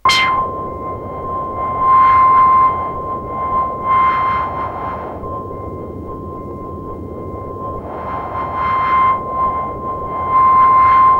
SI2 RAINEC0D.wav